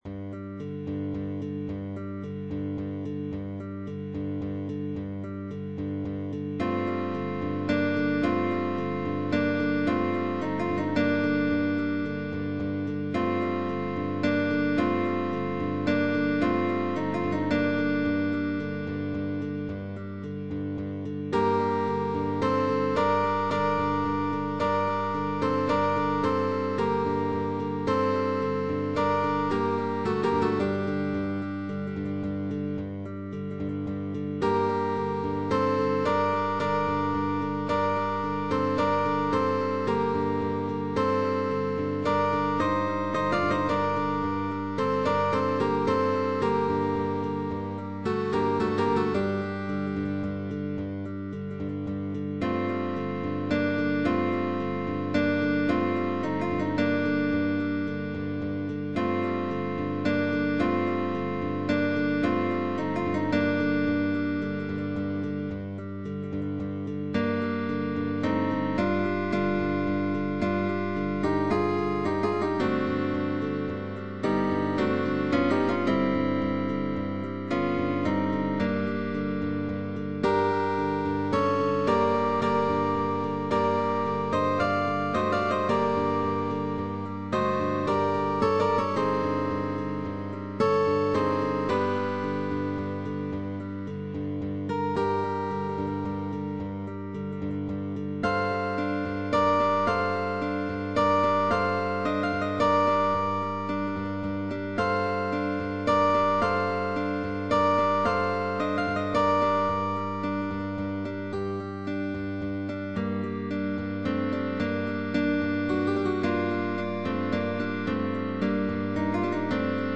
Partitura para cuarteto de guitarras.
CUARTETO de GUITARRAS